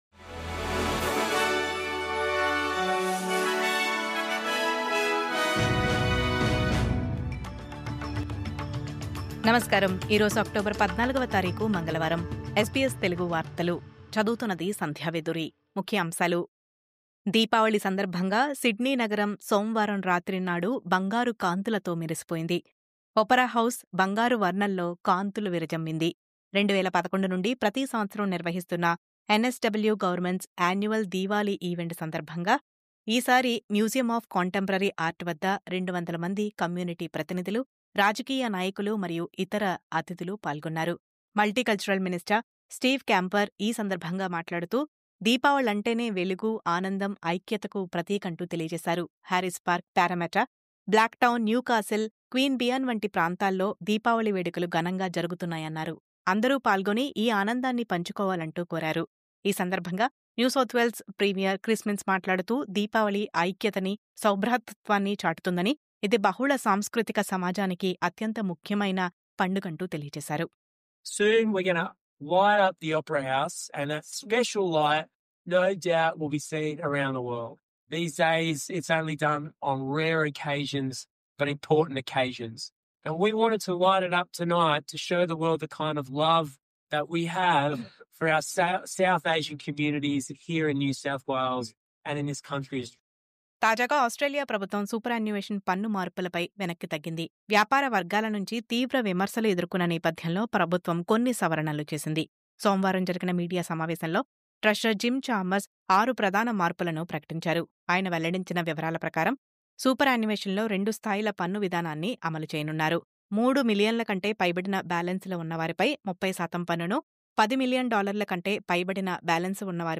News Update: NSW దీపావళి పండుగ సందర్భంగా బంగారు కాంతులతో మెరిసిన సిడ్నీ Opera House..